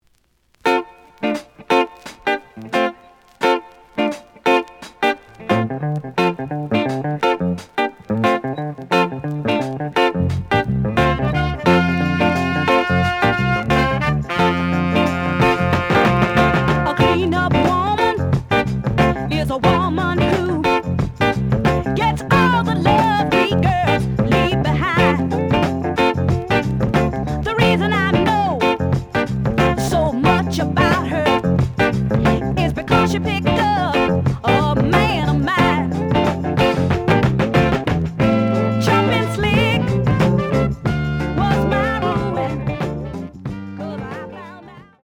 The audio sample is recorded from the actual item.
●Genre: Soul, 70's Soul
Edge warp. But doesn't affect playing. Plays good.)